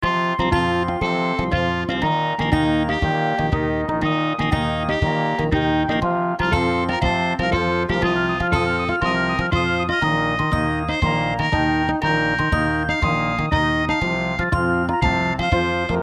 音量は大きめで録音してあるので、ご使用の際はゲインを絞ったり用途に合わせて下さい。
ミュートペットのすっとこどっこいな曲。